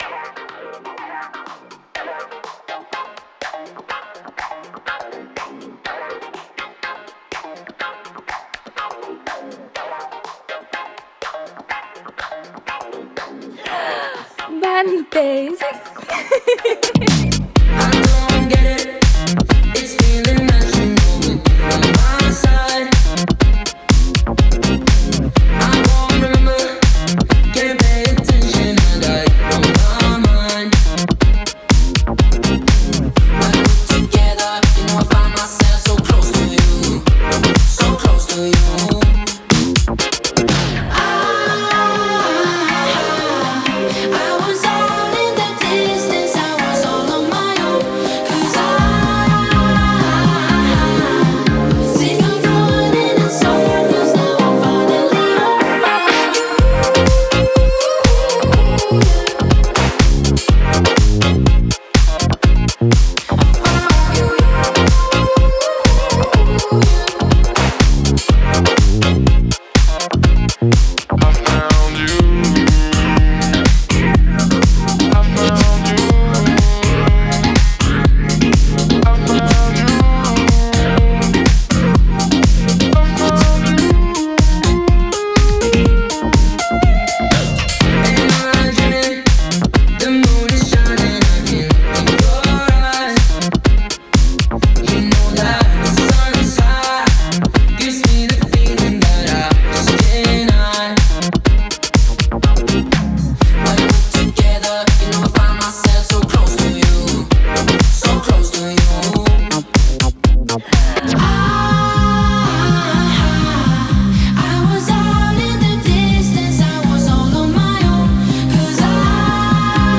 это яркая и энергичная поп-песня